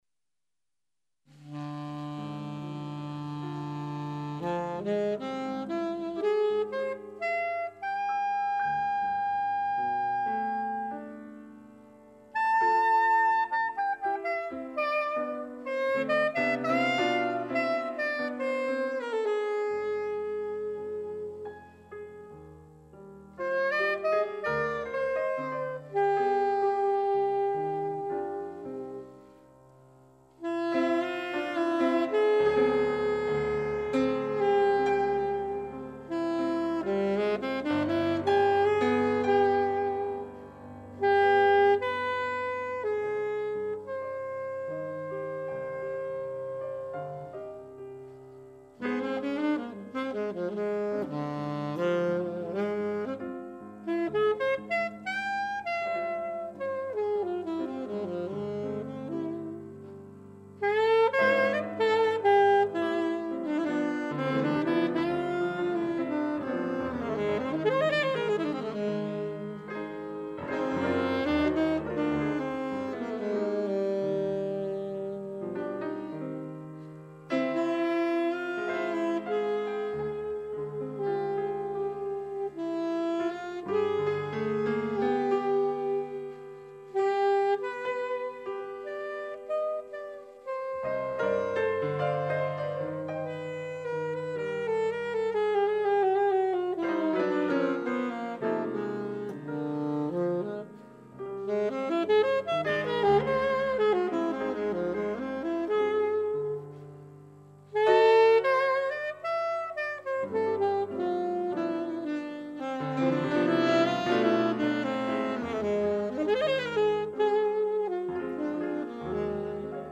alto saxophone
piano
drums